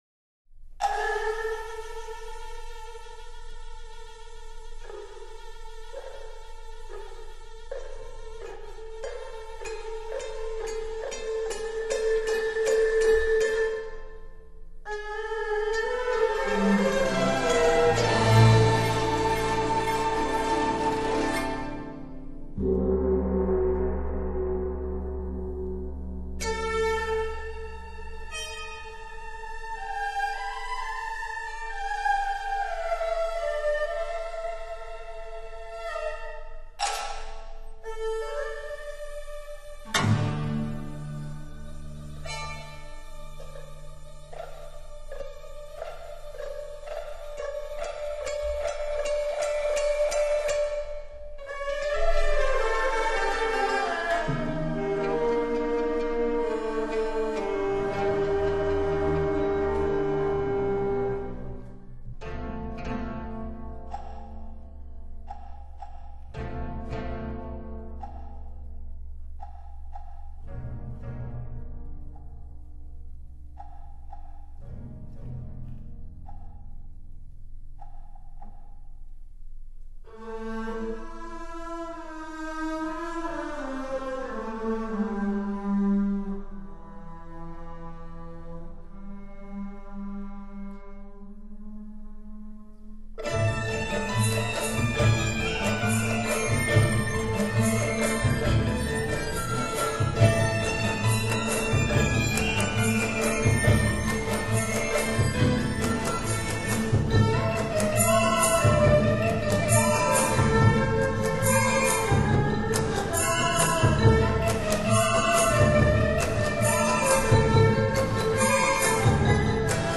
音乐类型: 协奏曲